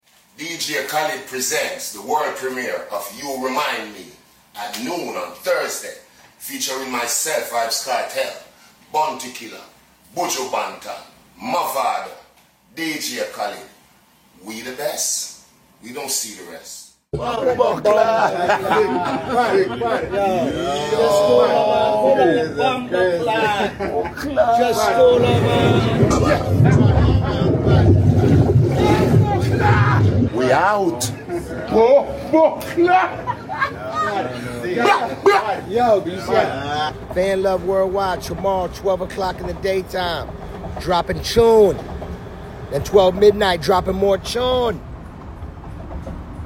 reggae/dancehall vibes